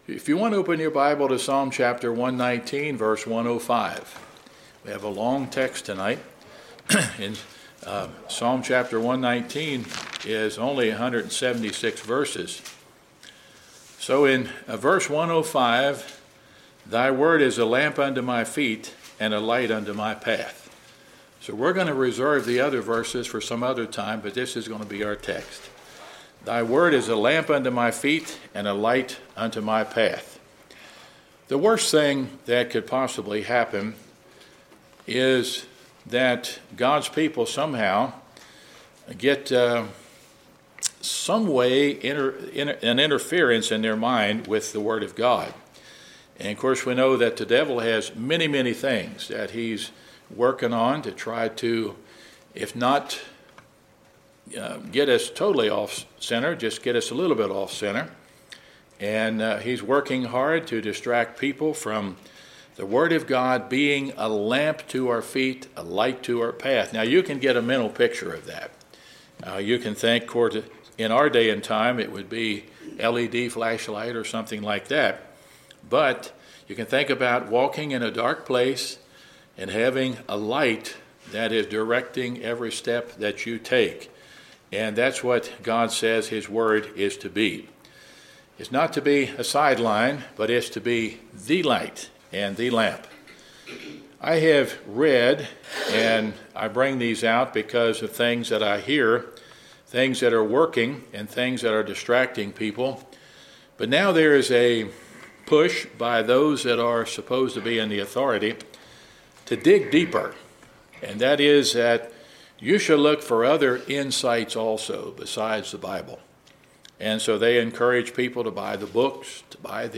Wednesday Sermon Book